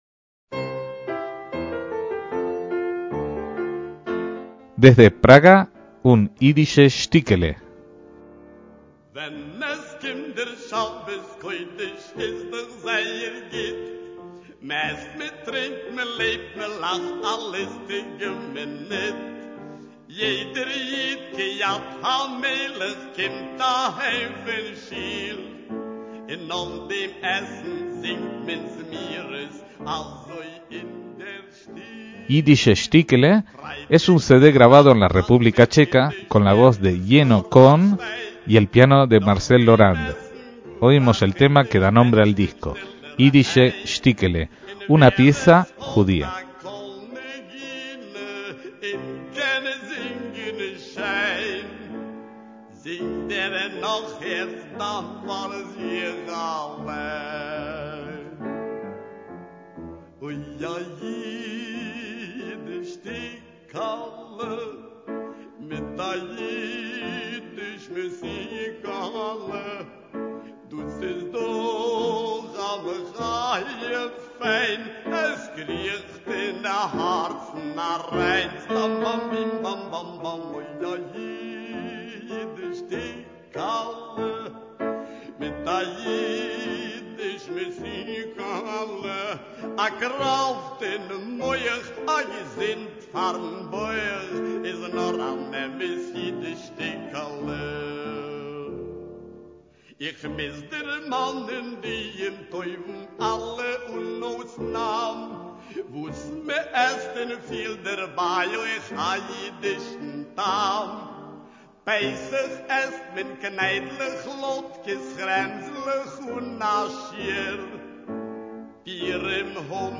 MÚSICA ÍDISH
con las interpretaciones en voz y piano